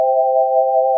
Single-cycle unison waveform
Created from sawtooth waveform of Analog Four.